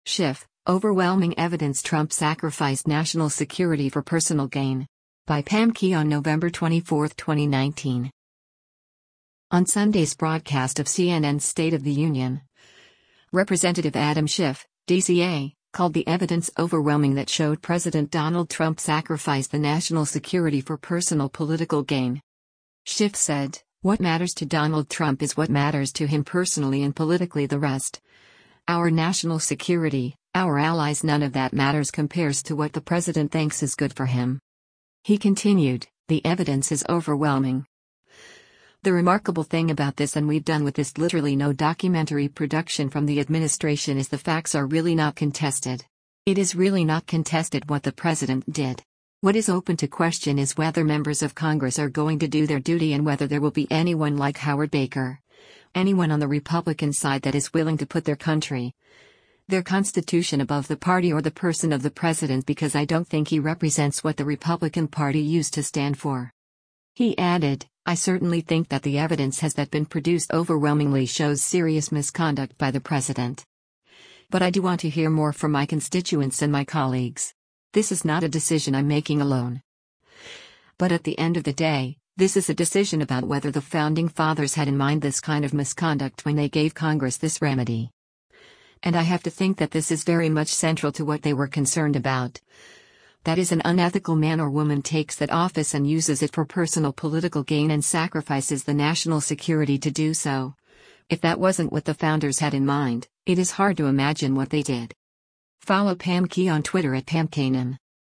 On Sunday’s broadcast of CNN’s “State of the Union,” Rep. Adam Schiff (D-CA) called the evidence “overwhelming” that showed President Donald Trump sacrificed the national security for “personal political gain.”